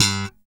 Index of /90_sSampleCDs/Roland - Rhythm Section/BS _E.Bass v_s/BS _P.Bs _ Slap